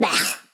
Expresión de repugnancia
exclamación
interjección
Sonidos: Acciones humanas
Sonidos: Voz humana